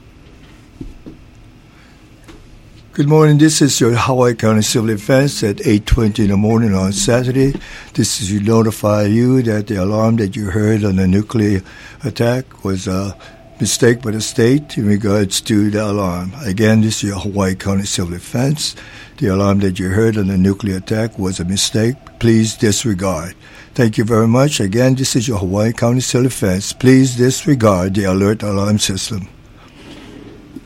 Hawaii County Mayor Harry Kim himself issued a civil defense audio message at 8:20 a.m. saying the alert was a mistake by the state.